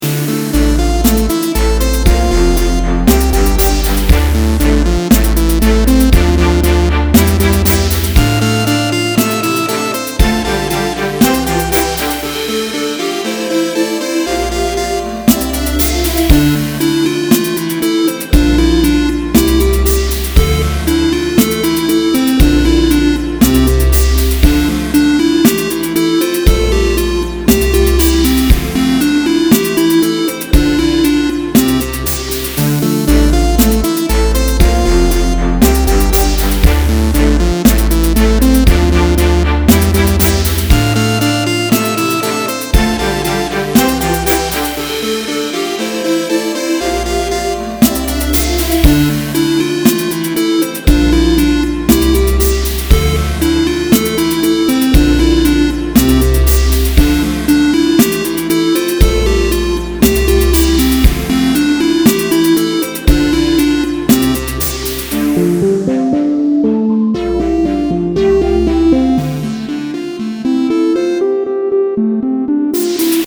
adds a layer of staccato violin